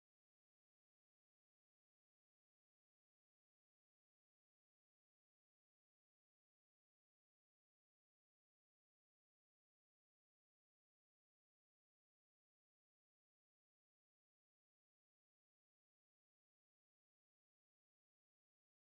drill (!)_Omnisphere [64bit] #2.wav